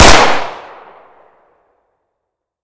Divergent / mods / Tommy Gun Drop / gamedata / sounds / weapons / thompson / thompson_3.ogg